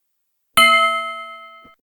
PetscopTalk-Tiara.ogg